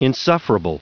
Prononciation du mot insufferable en anglais (fichier audio)
Prononciation du mot : insufferable